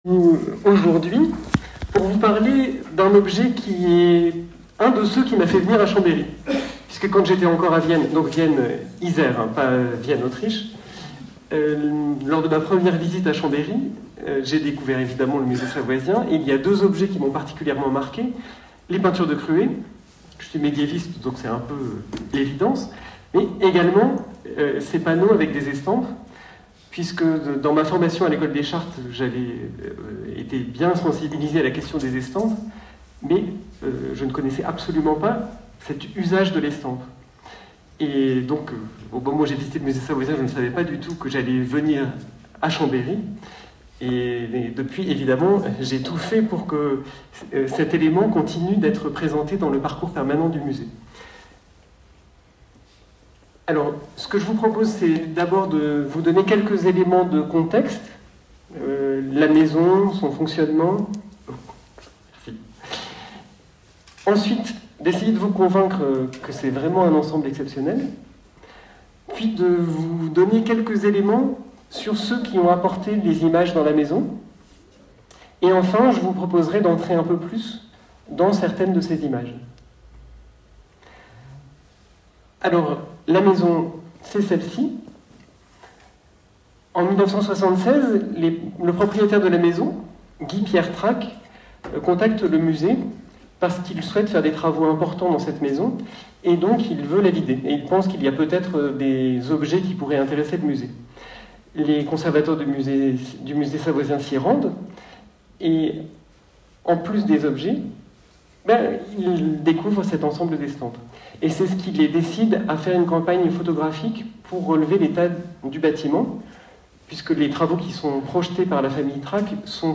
Enregistrement audio de la conférence du 14 février 2024 : (1:19:03)